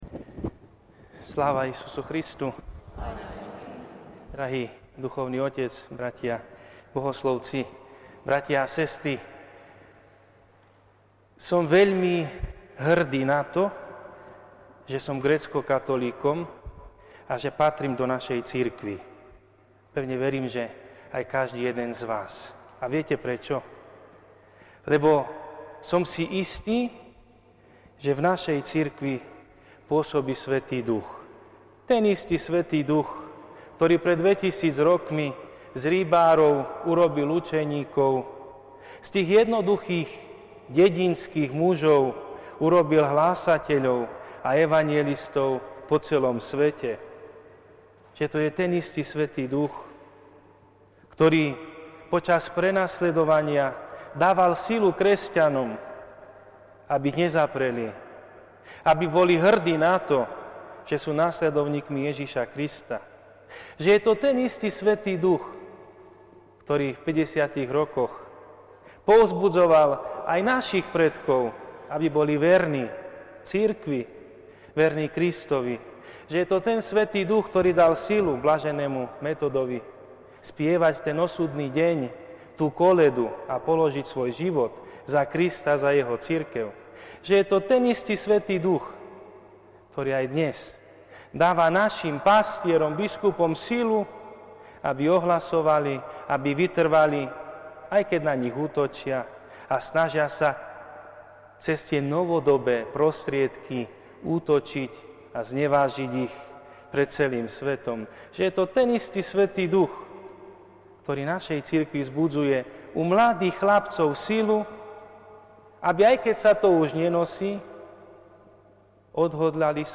V posledný májový víkend sa našej Bazilike minor uskutočnila Eparchiálna odpustová slávnosť Zostúpenia Svätého Ducha. Kvôli hygienickym opatreniam spôsobeným pandémiou COVID 19 sa celé odpustové dvojdnie konalo s obmedzeným počtom veriacich a kňazov. Celý program bol však vysielaný v priamom prenose TV Logos a TV Zemplín.